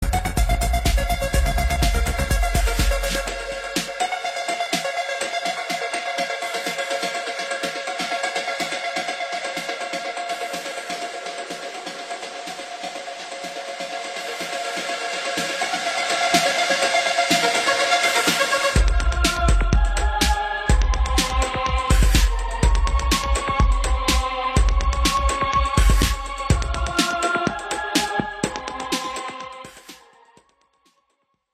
deep house
атмосферные
электронная музыка
без слов
nu disco
Indie Dance